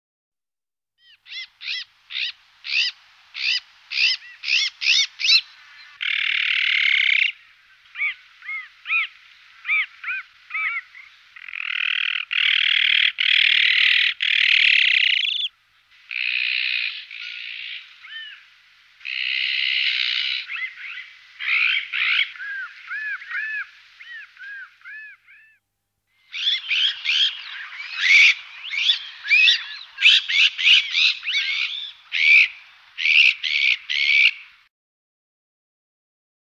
Вы можете слушать онлайн или скачать в формате mp3 её характерное стрекотание, карканье и другие варианты криков.
Звук крика сороки
Вокализация крика сороки